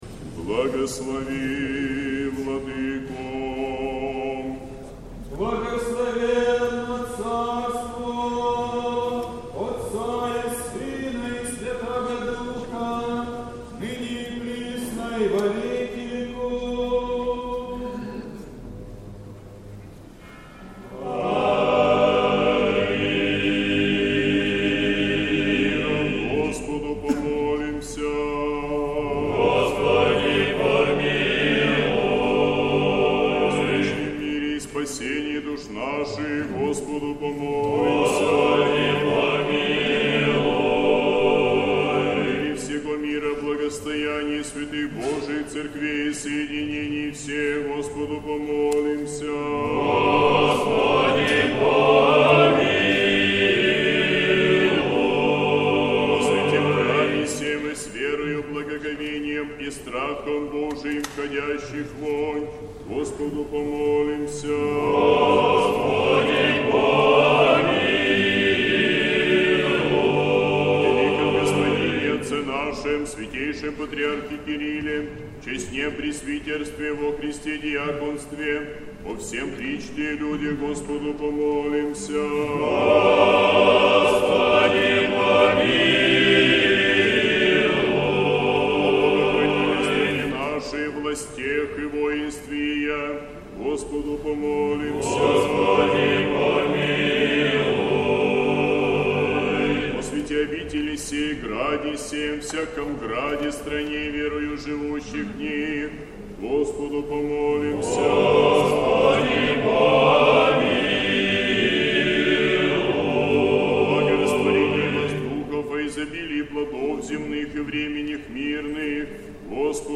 Божественная литургия в Сретенском монастыре на Воздвижение Честного и Животворящего Креста Господня
Божественная литургия. Хор Сретенского монастыря.